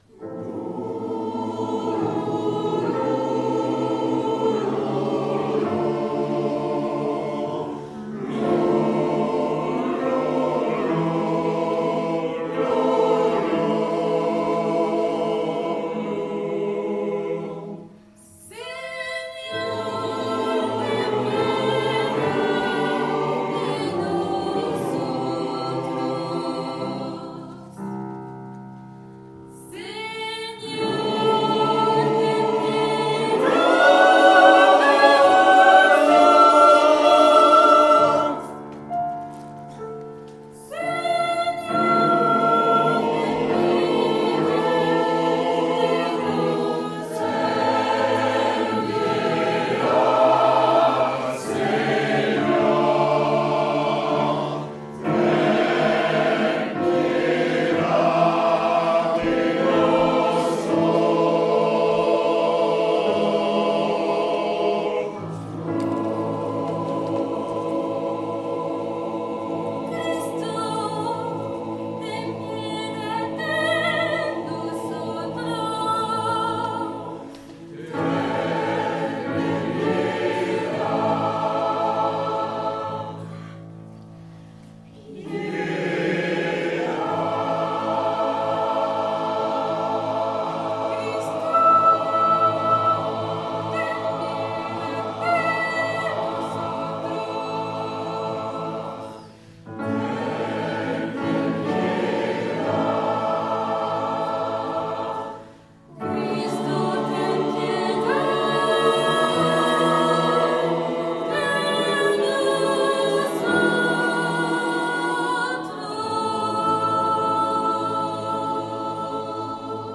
Enregistrements Audios du concert: